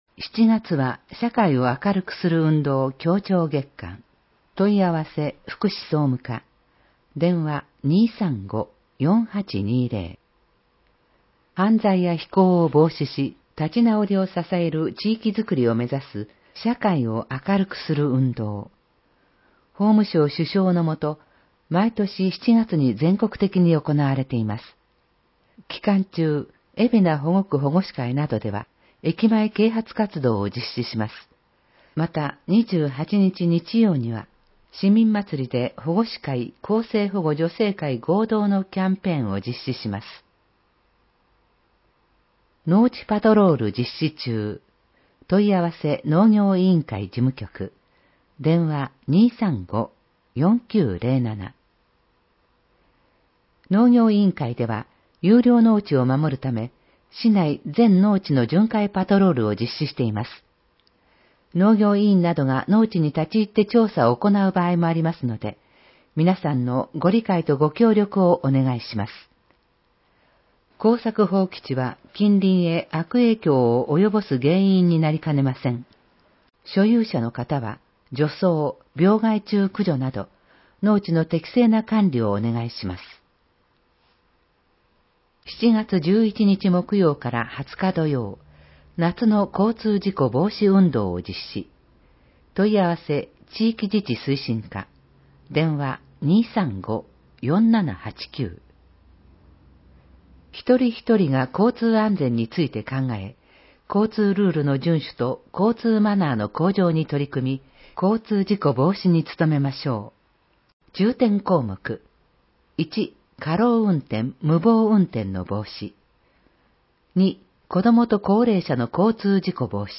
※音声版は、音声訳ボランティア「矢ぐるまの会」の協力により、同会が視覚障がい者の方のために作成したものを登載しています。